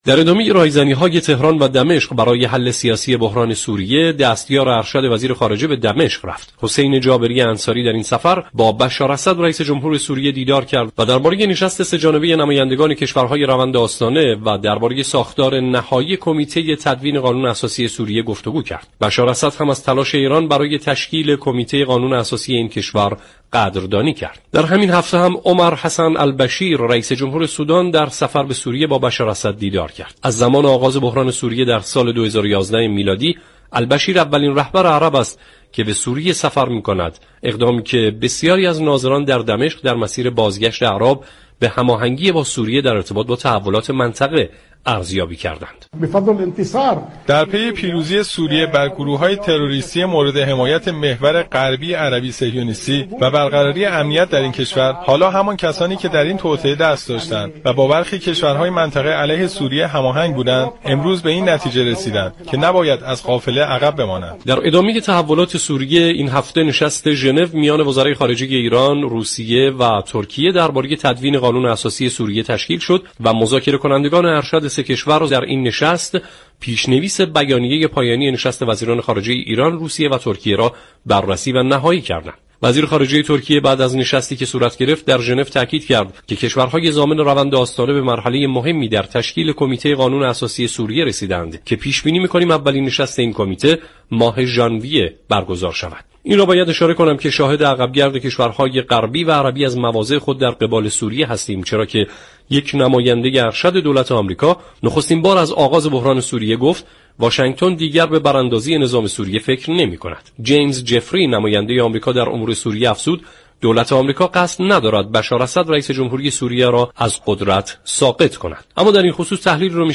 گفت و گو كرده است.